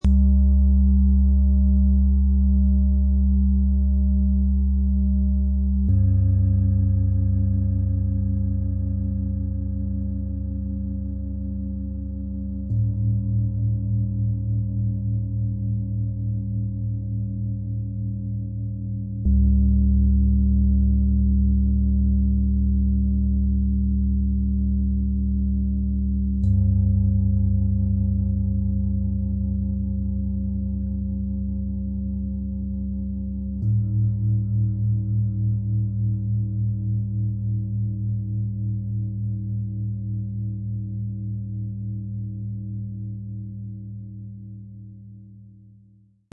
Profi-Klangmassage-Set „Kraft spüren, klar denken, innerlich ruhig bleiben“ aus 3 Biorhythmus-Klangschalen, Ø 22,1 -27,6 cm, 4,42 kg
Das Profi-Set für die Klangschalen-Massage schwingt den Körper von unten nach oben durch – kraftvoll unten, sanft und stabil oben.
Tiefster Ton: Biorhythmus Körper – Tageston
Zusatz für Profis: Tief bewegend, freundlich, intensiv schwingend, berührend – genau das, was eine Massage unten braucht.
Zusatz für Profis: Sanft, harmonisch, freundlich – angenehm tragend für den Oberkörper, ohne zu starke Vibrationen, die bei sensiblen Kunden Ängste auslösen könnten.
Zusatz für Profis: Freundlich, innerweichend und stabilisierend – oben sanfter, unten kraftvoll.
Im Sound-Player - Jetzt reinhören hören Sie den Original-Ton genau dieser drei Schalen – so, wie sie gemeinsam den Körper von unten nach oben tragen, berühren und ausklingen.